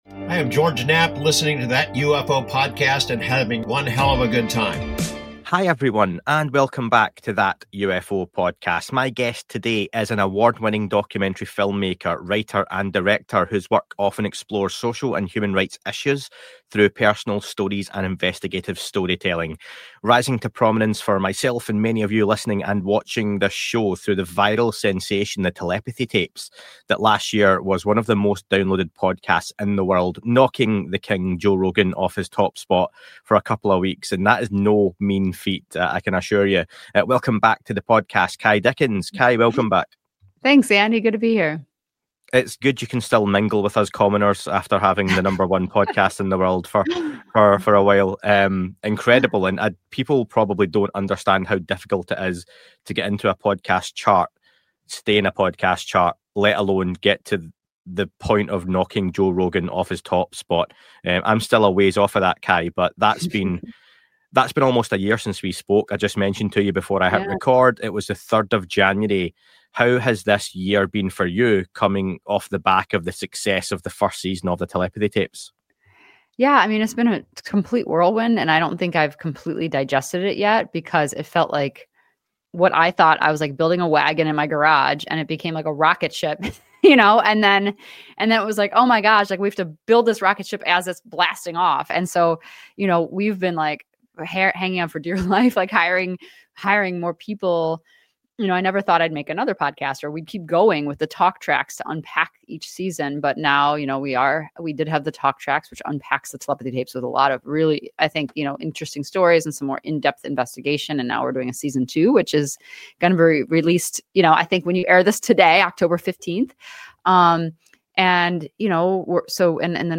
In this conversation, we explore the viral success of Season 1, the scientific studies involving non-speaking individuals, and what to expect from the upcoming documentary and Season 2.